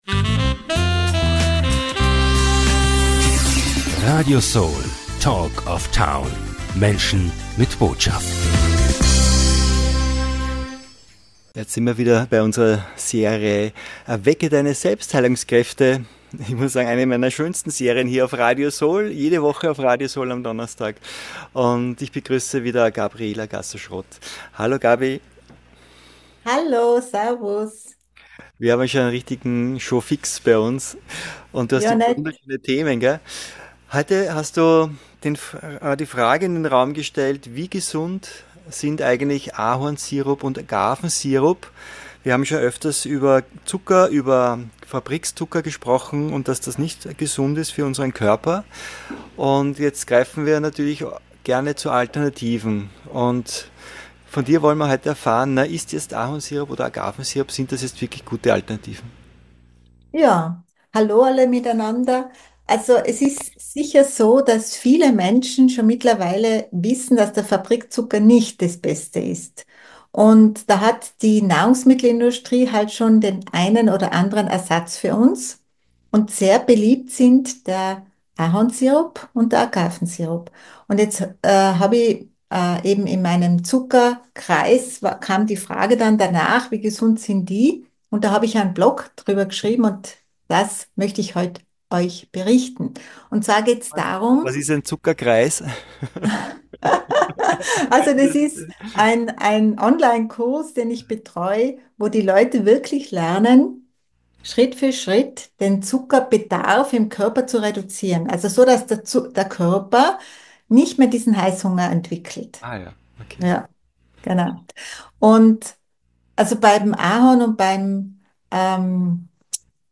Herstellung, Wissenswertes, alles rund um die Süßungsalternativen Ahornsirup und Agavensirup erfahren Sie in diesem herzlichen Interview!